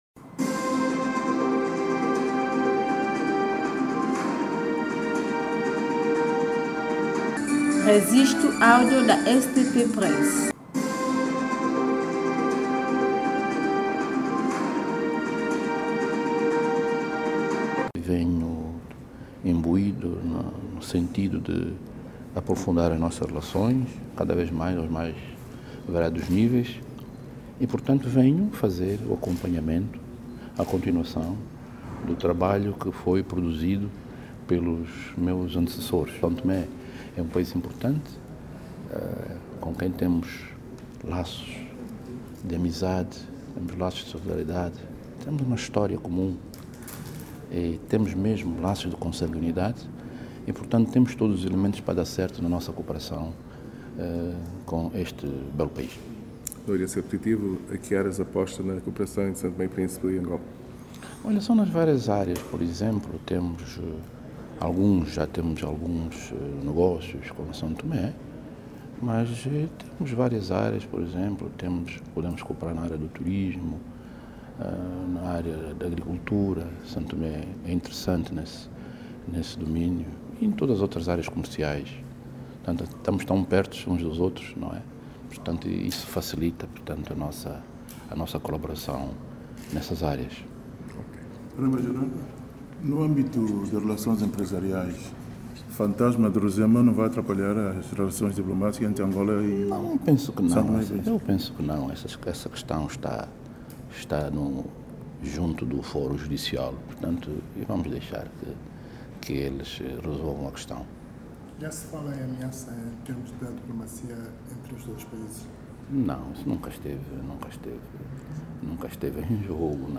Joaquim Duarte Pombo fez estas declarações a saída de uma audiência, no Palácio Presidencial em São Tomé, após a entrega de cartas credenciais ao Chefe de Estado são-tomense, Evaristo Carvalho.
Declaração do Novo Embaixador de Angola para STP, Joaquim Pombo